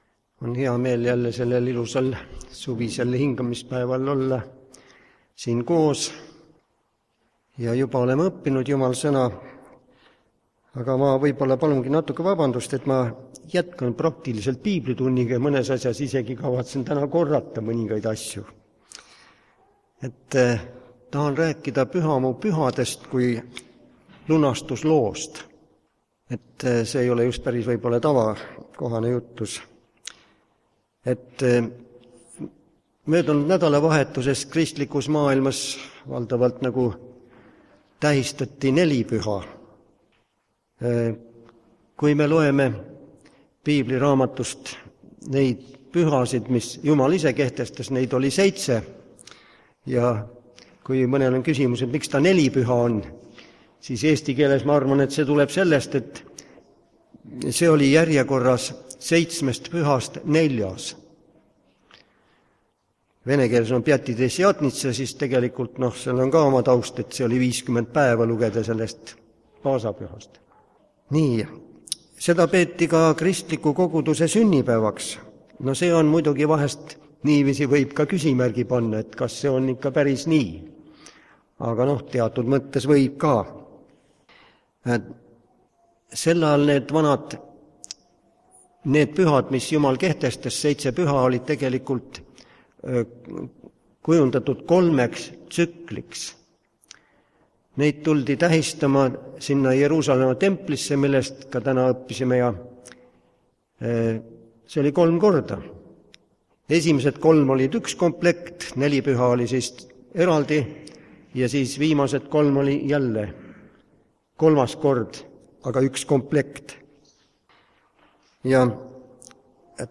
kategooria Audio / Jutlused
Tänase jutluse teemaks on JUMALA SEITSE PÜHA Laulame koos ka laulu (sõnad siin)